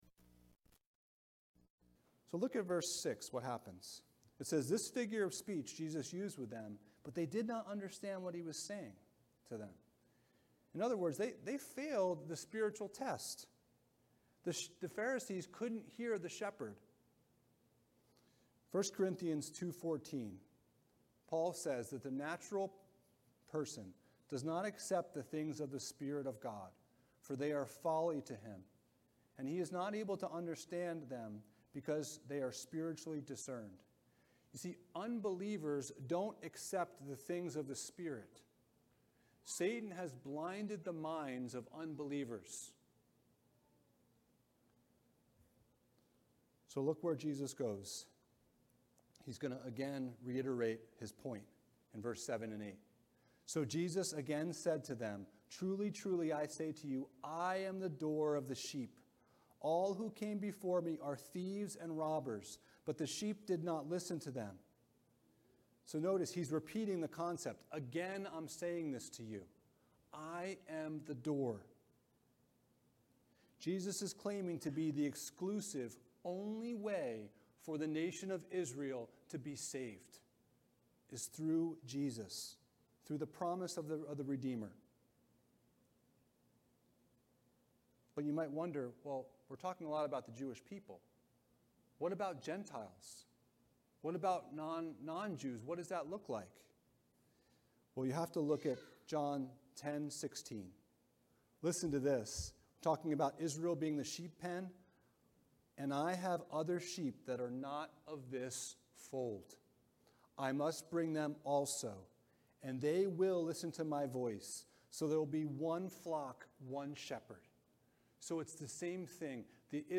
Due to technical issues, only the second half of the sermon was recorded